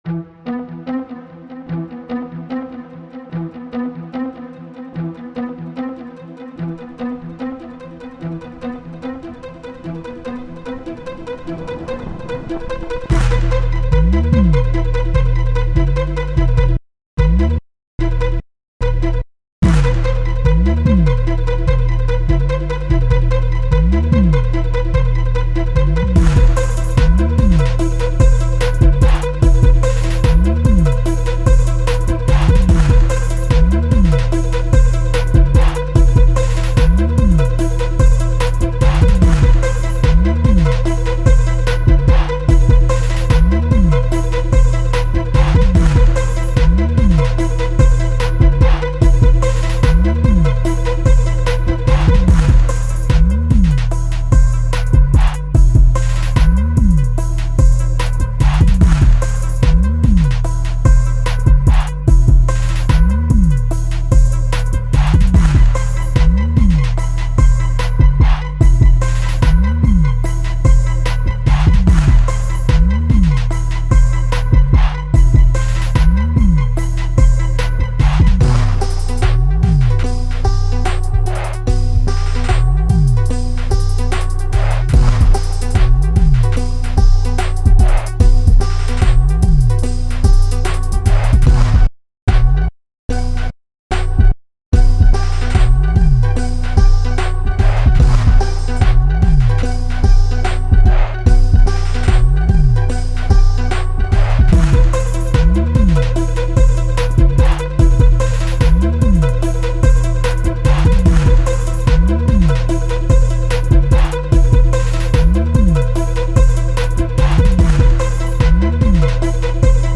i make trap music too . bpm: 140 fun
hiphop wild go crazy
heavy 808